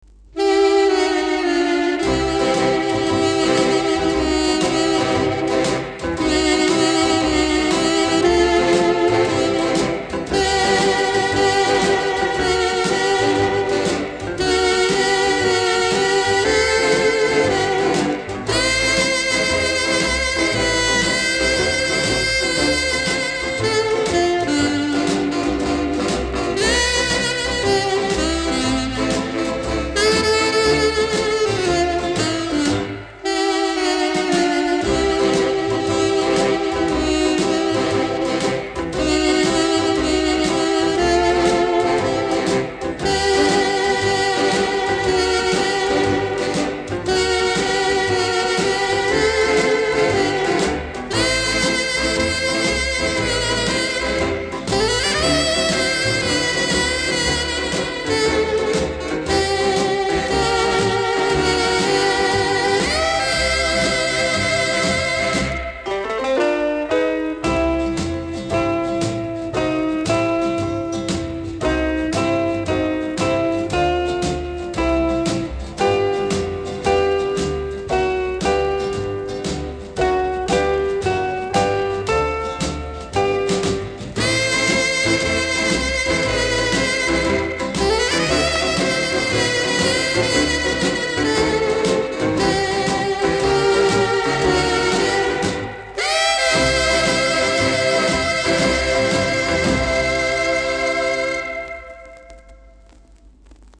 日本顶级流行乐团演奏，来自日本舞池的激情。
黑胶转录日期：2022年6月18日PM